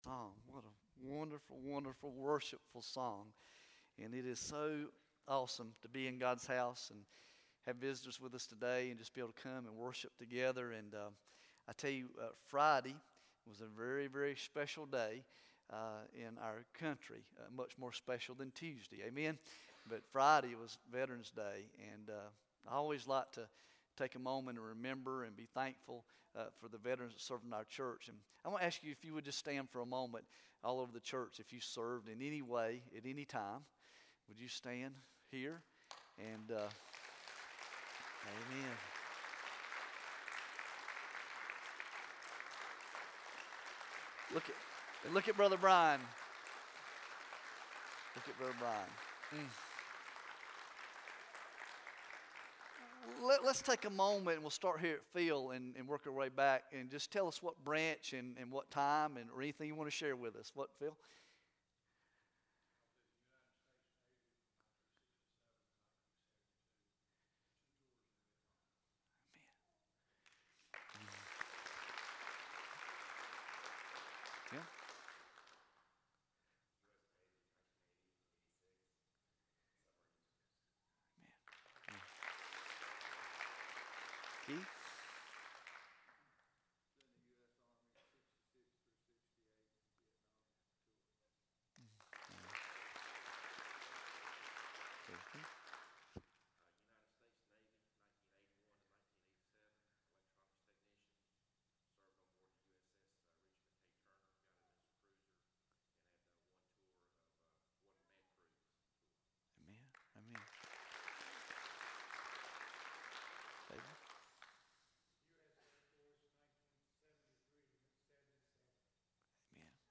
Sunday November13, 2016 AM Hamilton Cross Roads Baptist Church Sermons podcast